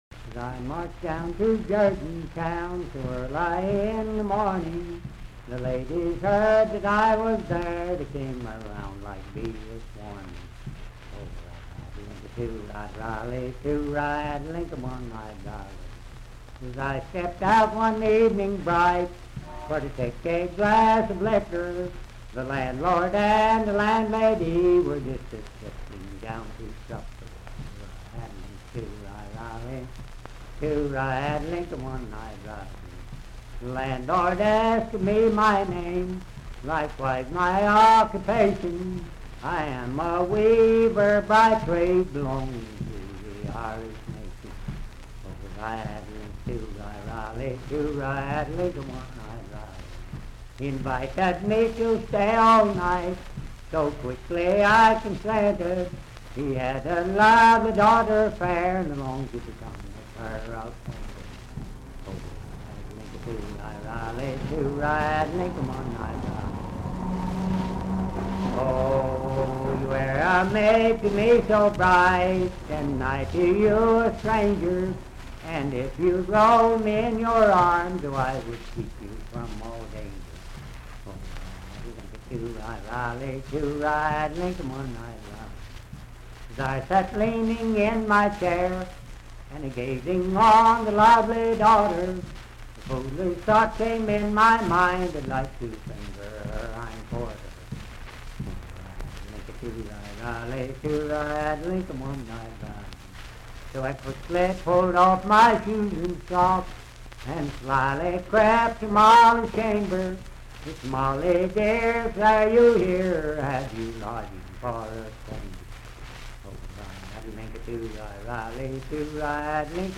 Unaccompanied vocal music
Bawdy Songs
Voice (sung)
Wood County (W. Va.), Parkersburg (W. Va.)